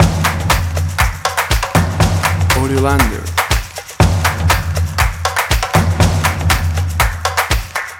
ENERGY DRUMS LOOP - 0:08"
An exciting and fresh mix of drums, made of stomps, finger snaps, hand claps, real drum kit and cool electro rhythmic elements that make it a great choice for action scenes, dynamic video games, sport videos, advertising, motion graphics, podcast intros and more.
WAV Sample Rate: 16-Bit stereo, 44.1 kHz
Tempo (BPM): 120
Energy-Drums-LoopCS.mp3